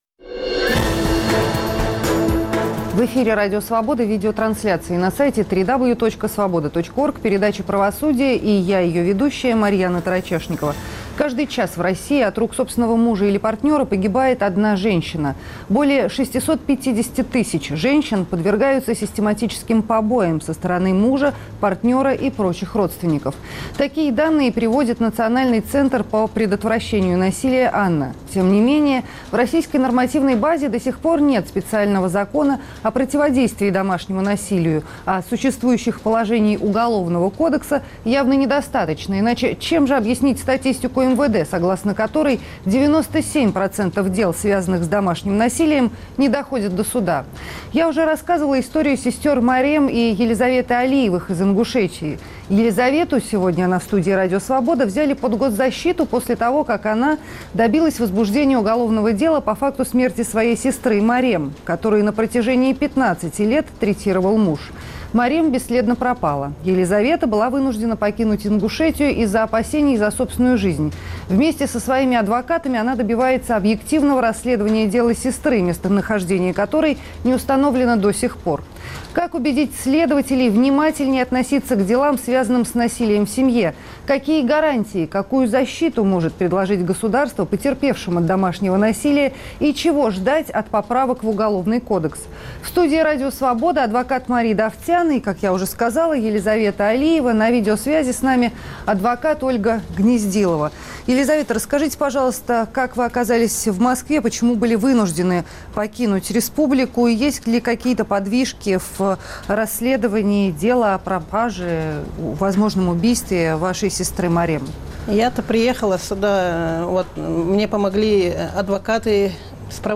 Как в России защищают потерпевших от домашнего насилия? В студии Радио Свобода
На видеосвязи